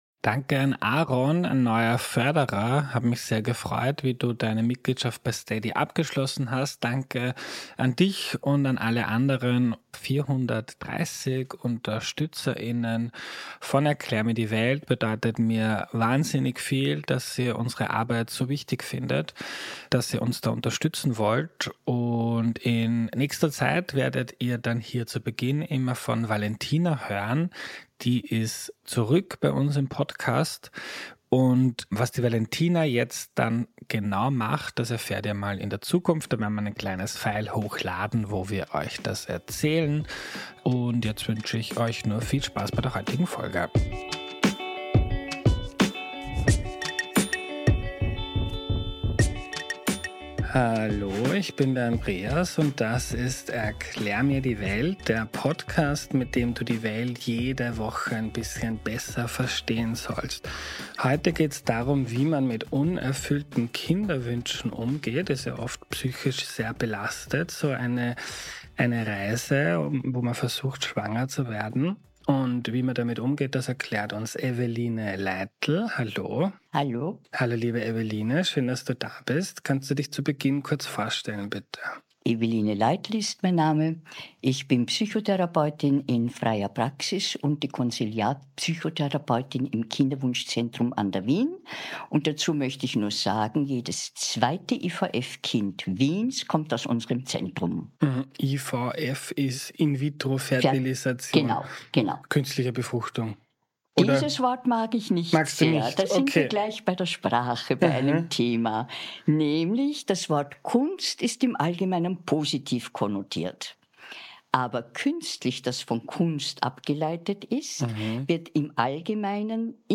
Ein Gespräch über Tabus, Trauer und neue Lebensperspektiven.